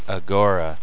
"ag'-er-uh" "uh-gor'-uh"
uhgoruh.wav